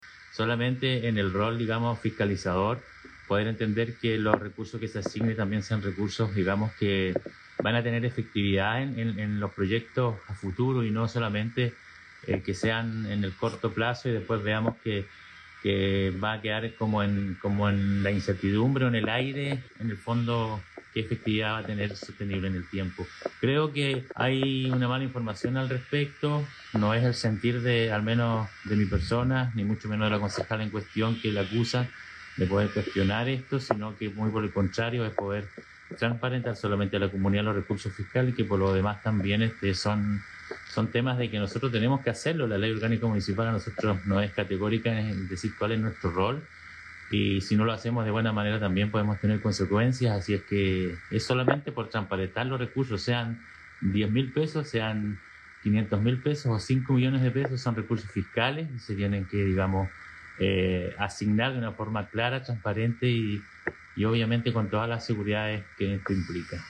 A estas situaciones, se refirió a través de Hito Cero Televisión, el concejal de la comuna de Quellón Cristian Chiguay, quien refirió que las menciones que se han hecho sobre este proyecto tiene que ver con la necesaria transparencia que debe existir en estas iniciativas, en las que hay involucrados recursos públicos.